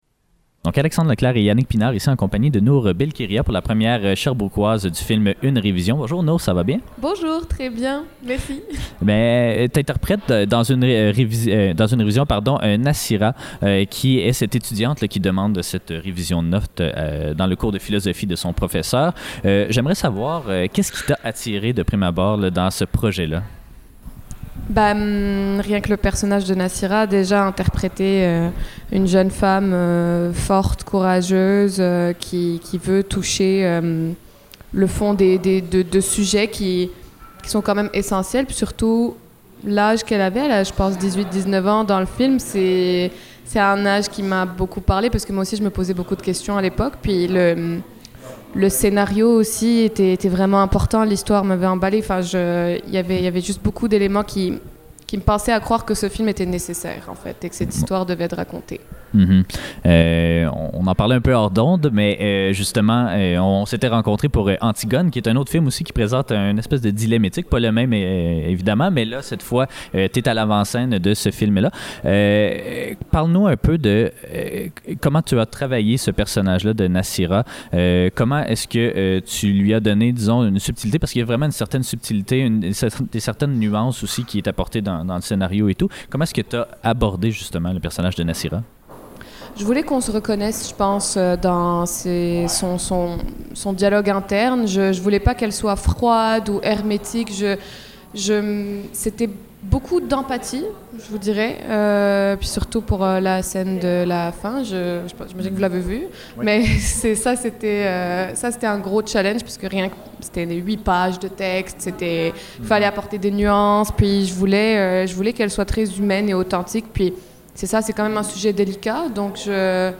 Ciné-histoire - Entrevue